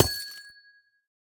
Minecraft Version Minecraft Version 1.21.5 Latest Release | Latest Snapshot 1.21.5 / assets / minecraft / sounds / block / amethyst / break1.ogg Compare With Compare With Latest Release | Latest Snapshot
break1.ogg